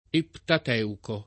Ettateuco
vai all'elenco alfabetico delle voci ingrandisci il carattere 100% rimpicciolisci il carattere stampa invia tramite posta elettronica codividi su Facebook Ettateuco [ ettat $ uko ] o Eptateuco [ eptat $ uko ] tit. m. bibl. — i primi sette libri del Vecchio Testamento